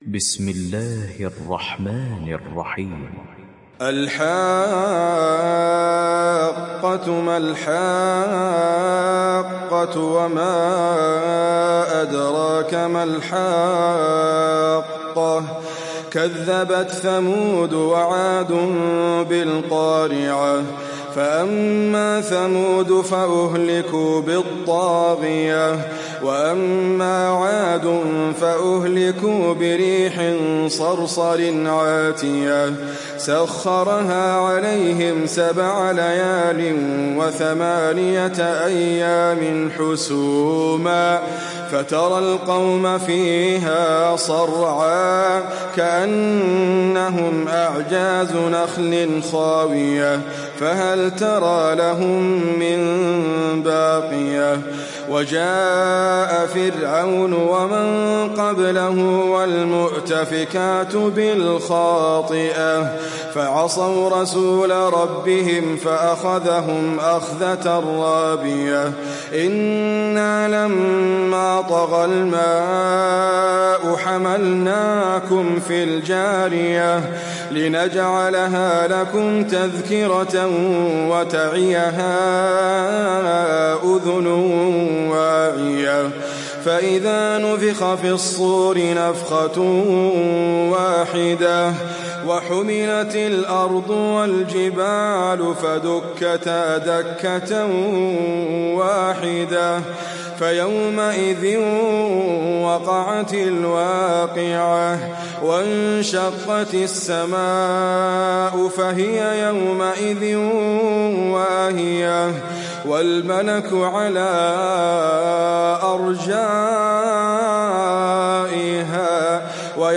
تحميل سورة الحاقة mp3 بصوت إدريس أبكر برواية حفص عن عاصم, تحميل استماع القرآن الكريم على الجوال mp3 كاملا بروابط مباشرة وسريعة